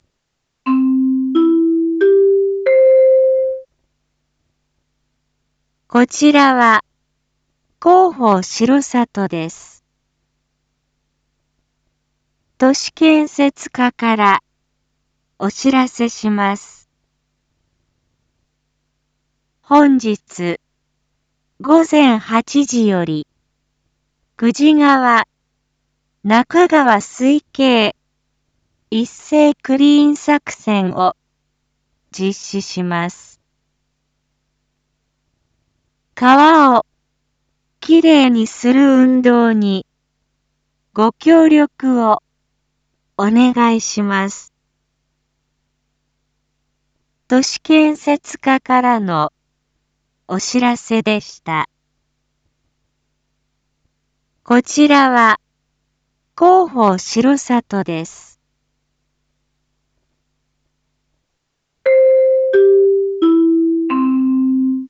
一般放送情報
Back Home 一般放送情報 音声放送 再生 一般放送情報 登録日時：2023-07-02 07:01:11 タイトル：「久慈川・那珂川水系一斉クリーン作戦」について インフォメーション：こちらは、広報しろさとです。